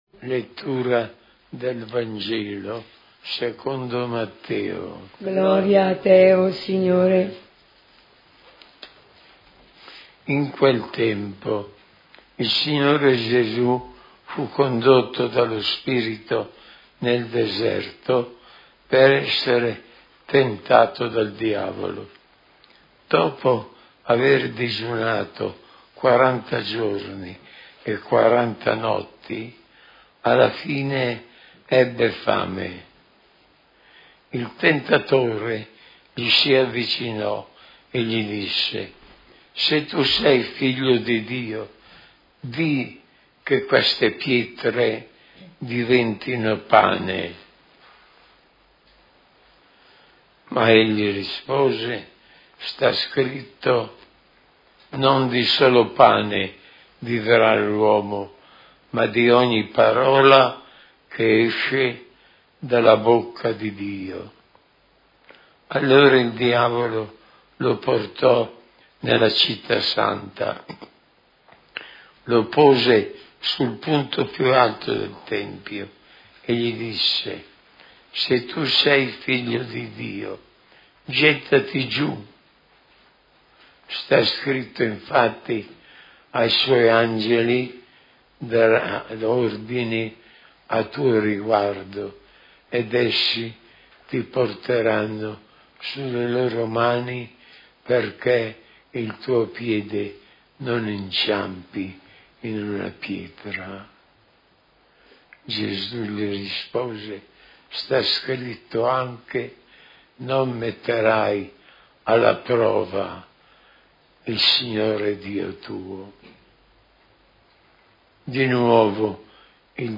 Santo Rosario in famiglia - Omelia S.Messa del giorno- Preghiere della sera